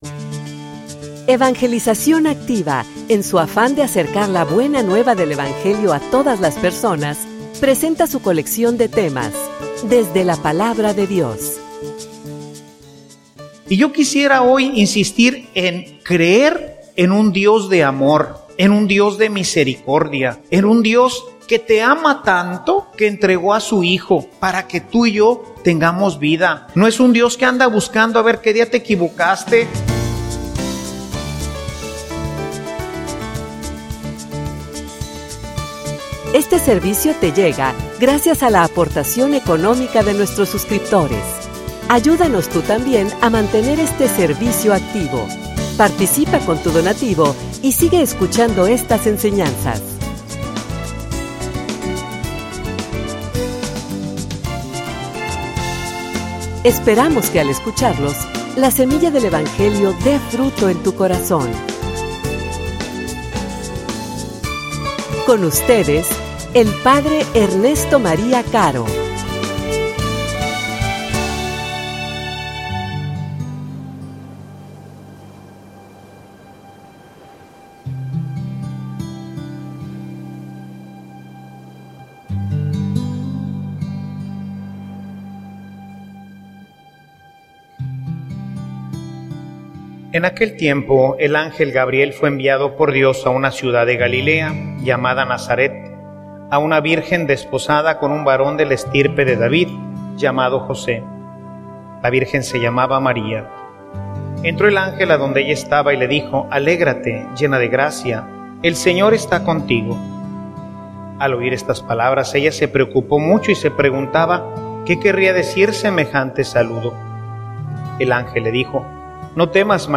homilia_Preparacion_para_el_encuentro_definitivo.mp3